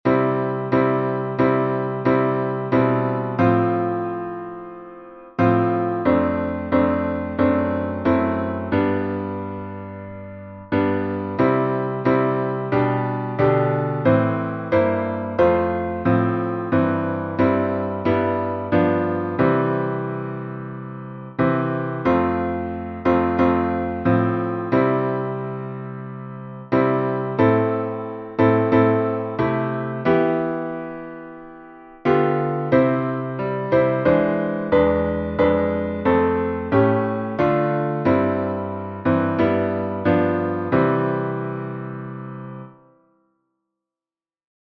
salmo_51B_instrumental.mp3